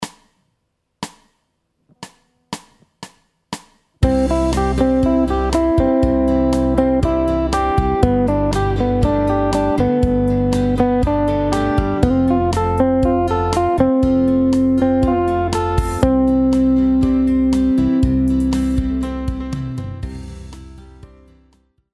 The Chord progression is looped C / G / F / C / and we will stay as close to third position (third fret) as possible with our soloing notes.
Start out by just repeating the patterns over the chord changes like in example 1 to get used to them.
Slow down, keep it simple and start digging that country sound!